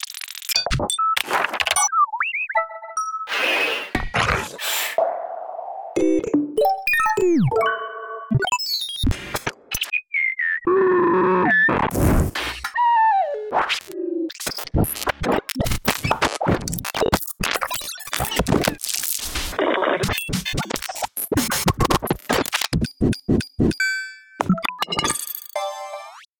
I'm regularly working on sound design for games.
randomsfx.mp3